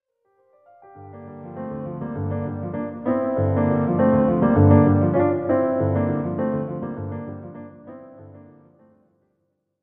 Music Transition; Modern Jazz Piano Fade In And Out.